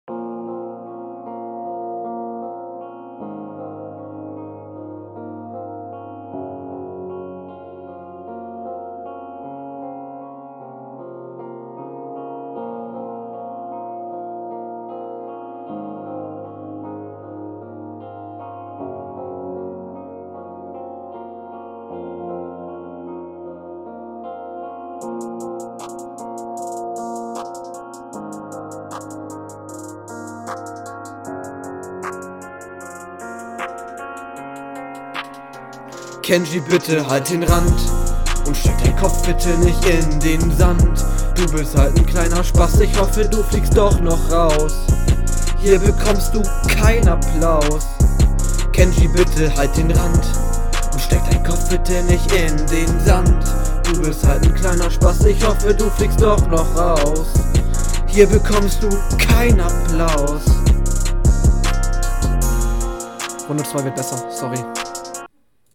Intro extrem lang bitte achte demnächst auf ein 30 Sekunden Intro.